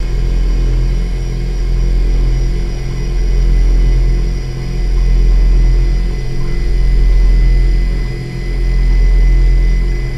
Motor Hum, Low Pitch Loop